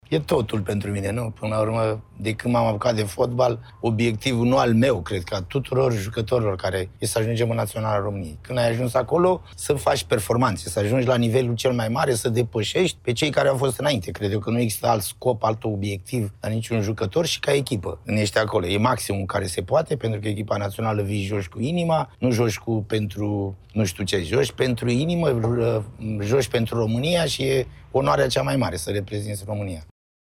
Gică Hagi reacționează după ce a cedat statutul de acționar majoritar la Farul Constanța „Vreau să mă întorc la antrenorat… și Naționala este obiectivul oricărui fotbalist”, sunt declarațiile sale într-un interviu pentru ProSport.